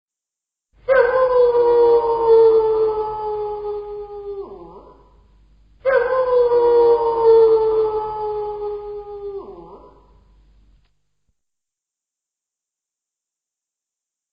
Coyote Ringtone
coyote_call.mp3